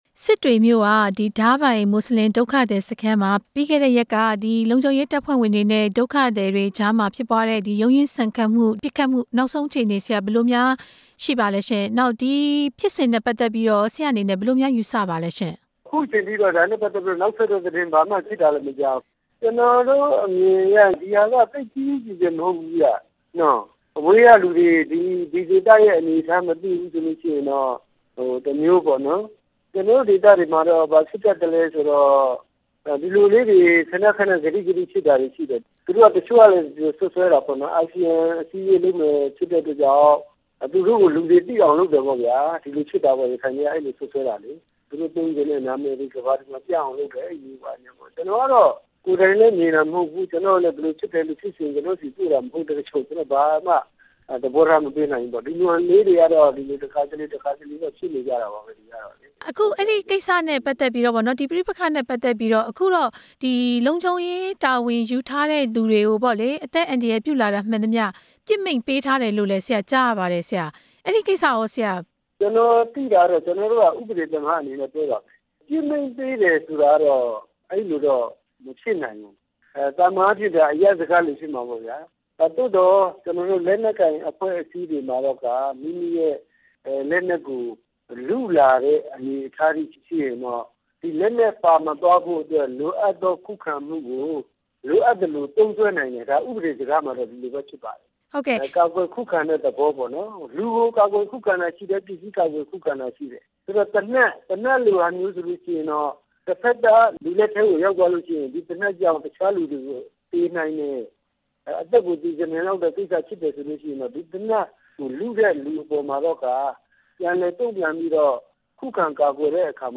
ဆက်သွယ်မေးမြန်းထားတာကို နားဆင်နိုင်ပါတယ်။